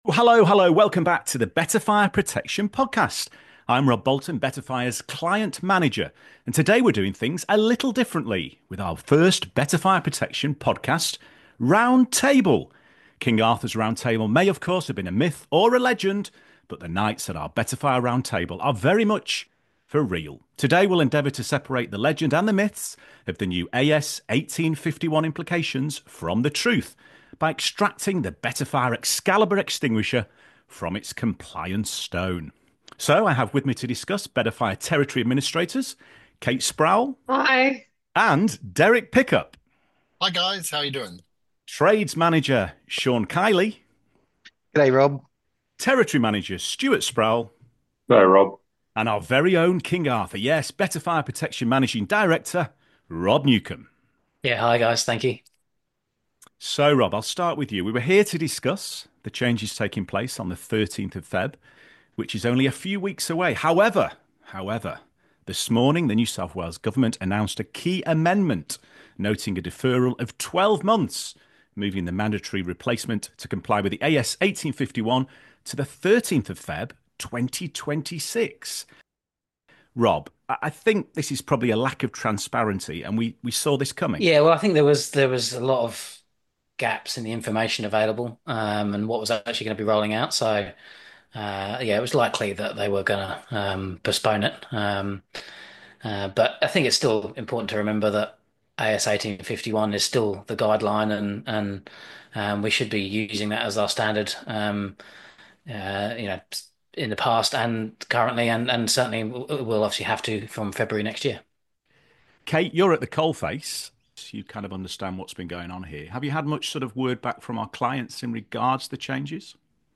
What a 12-month stall means for Fire Safety Compliance Season 1, Episode 7, Jan 28, 12:46 AM Headliner Embed Embed code See more options Share Facebook X Subscribe Join the Betta Fire Protection team for their first-ever roundtable discussion, featuring insights from client managers, territory administrators, and trades professionals. In this episode, the panel unpacks the New South Wales government’s recent decision to defer mandatory AS1851 compliance requirements by 12 months, pushing the deadline to February 2026.